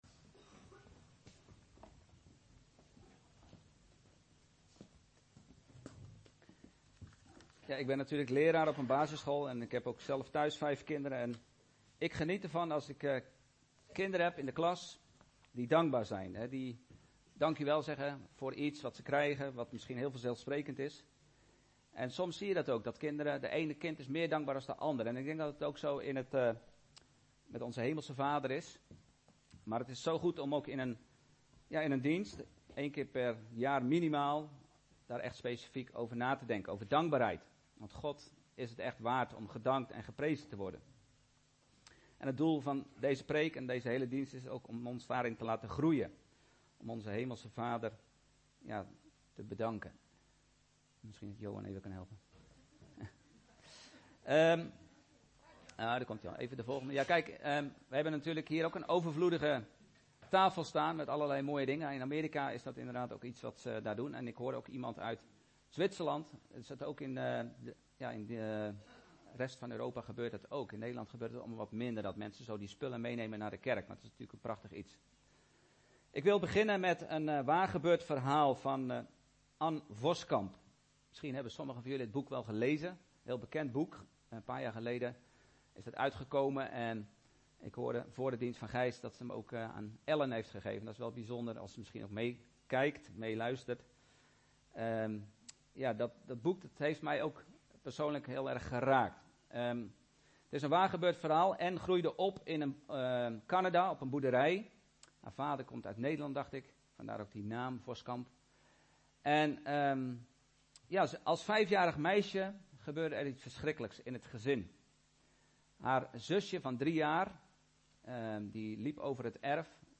Een preek over 'Duizendmaal dank!'.